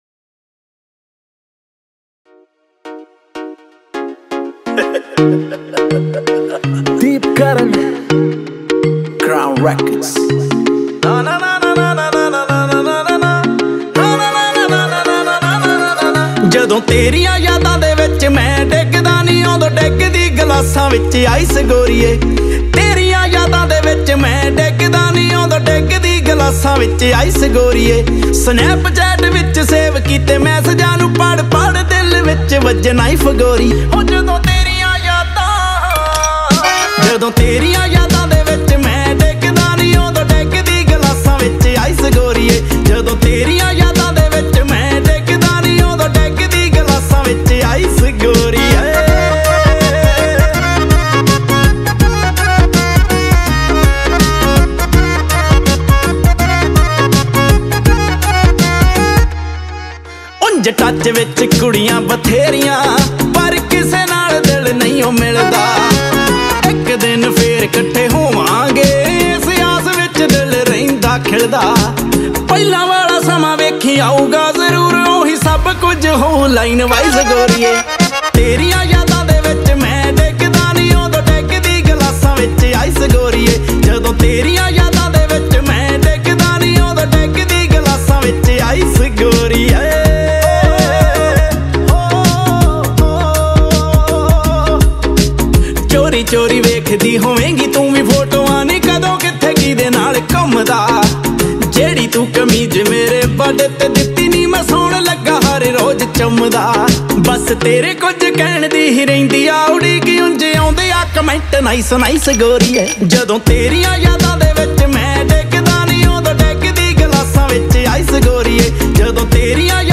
Category :Punjabi Music